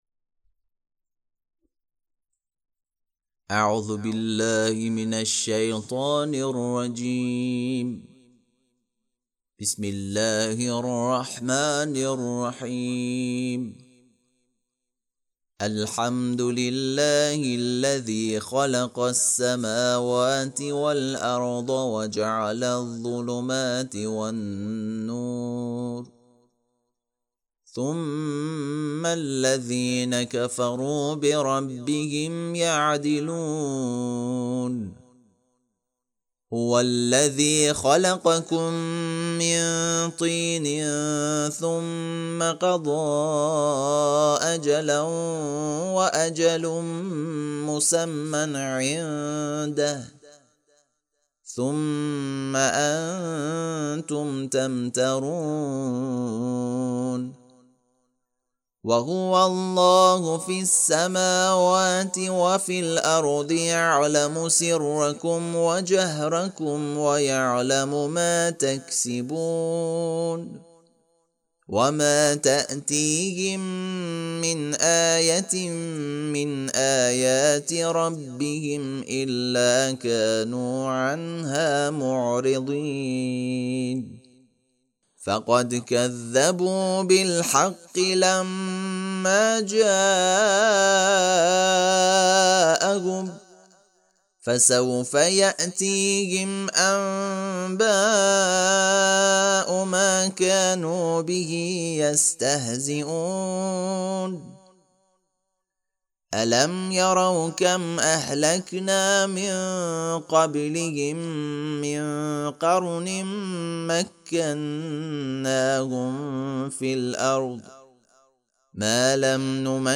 ترتیل صفحه ۱۲۸ سوره مبارکه انعام(جزء هفتم)
ترتیل سوره(انعام)